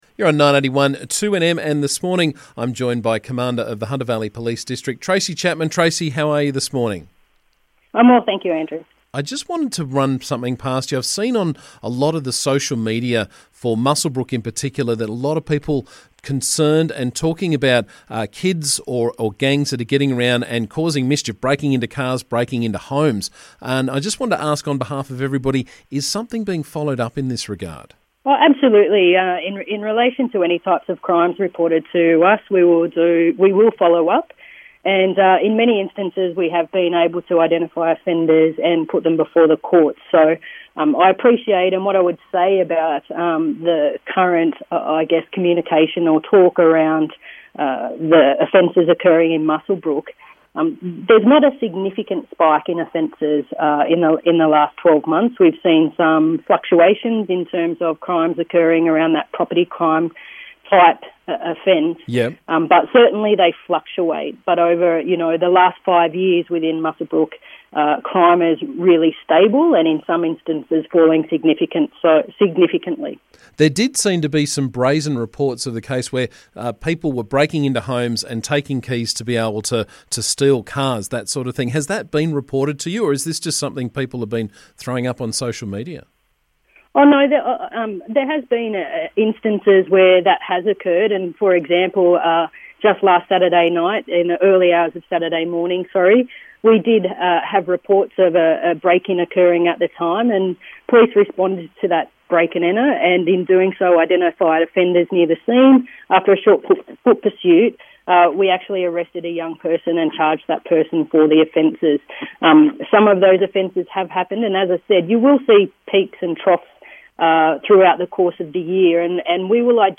There's been a spate of break ins and car thefts in the MUswellbrook area of late so we caught up with Hunter Valley Police Commander Tracy Chapman to find out what is being done.